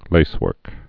(lāswûrk)